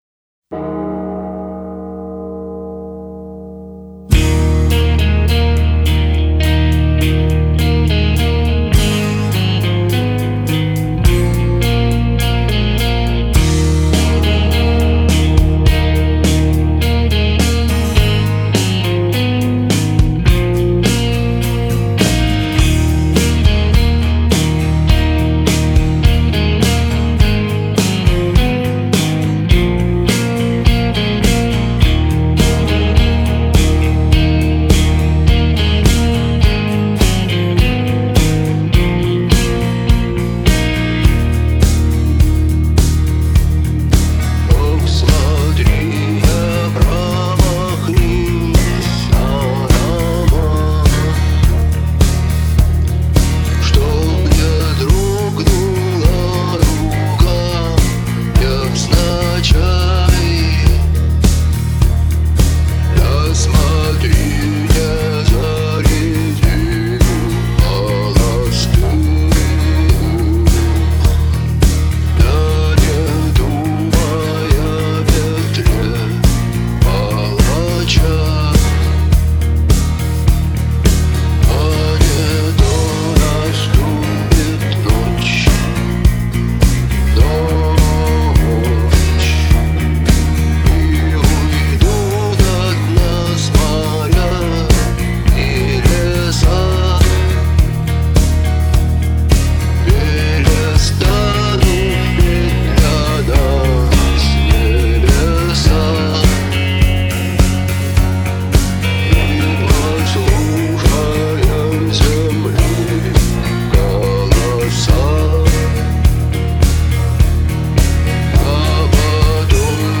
записал 22 года назад на магнитофон